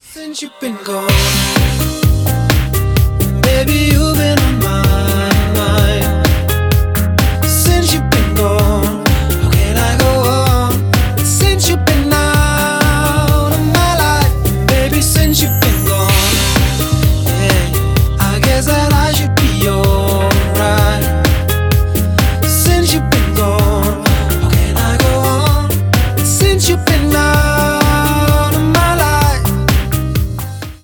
Романтические рингтоны
Спокойные рингтоны
поп , мужской вокал